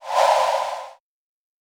Techmino/media/effect/chiptune/swipe.ogg at f6f4e1cd1ad2cc2fea6bedb151a42aa12d5873b5
swipe.ogg